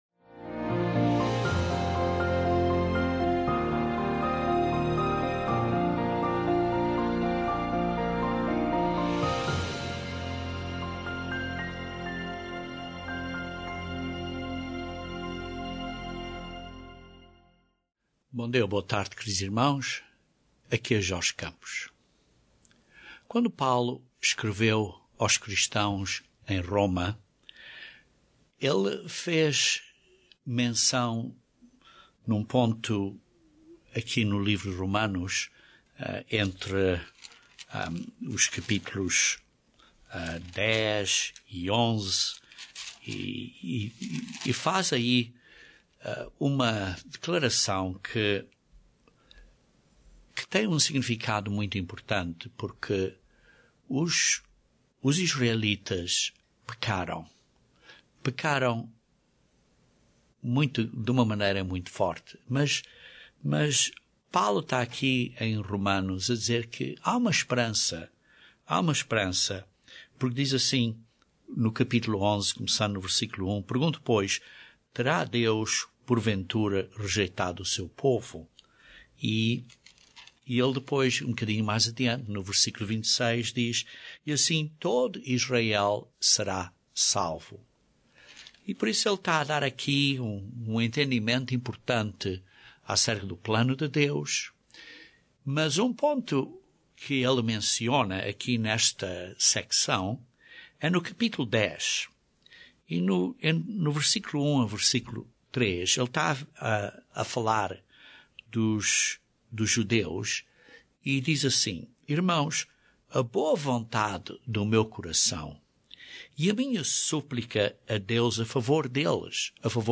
Como é que você pode buscar a justiça de Deus? Este sermão descreve uns pontos baseados no exemplo da maneira de orarmos que Jesus nos deu.